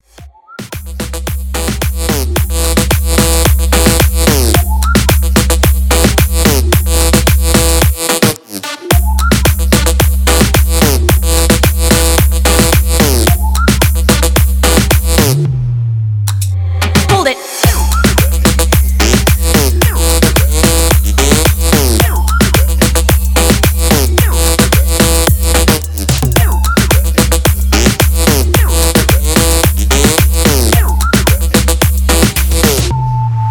Elektronisk musik, Android, Whistle